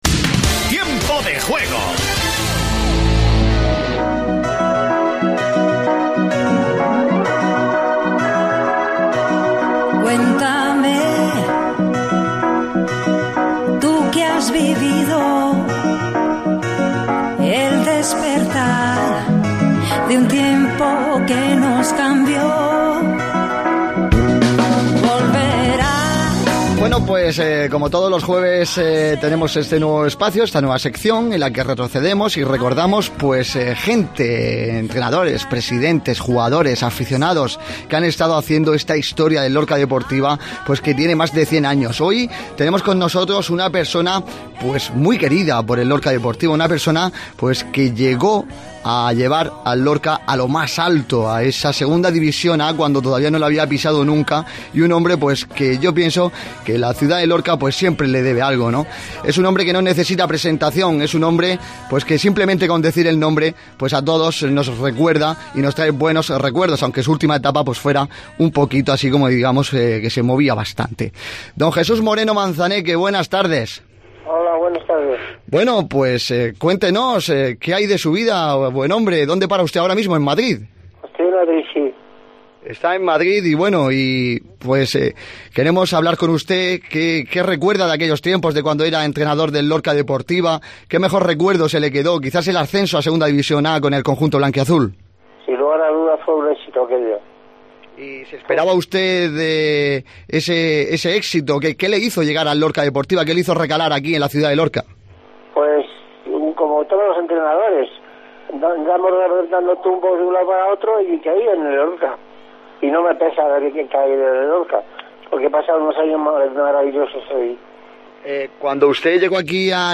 AUDIO: Entrevista